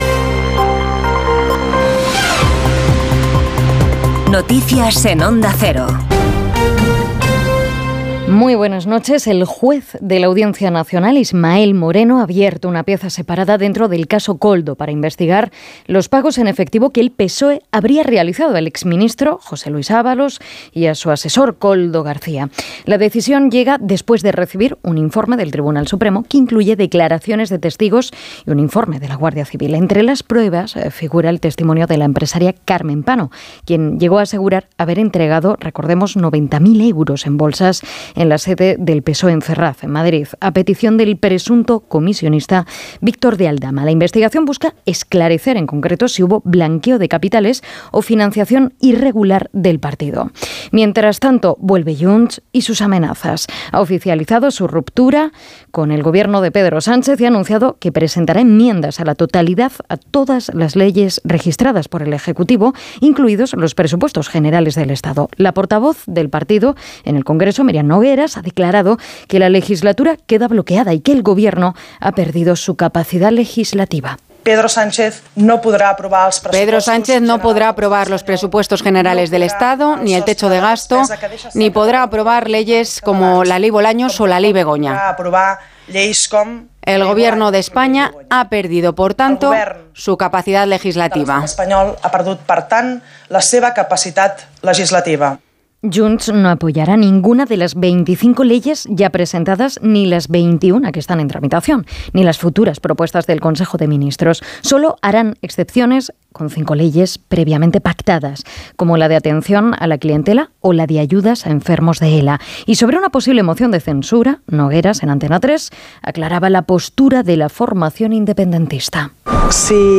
Conoce la ultima hora y toda la actualidad del dia en los boletines informativos de Onda Cero. Escucha hora a hora las noticias de hoy en Espana y el mundo y mantente al dia con la informacion deportiva.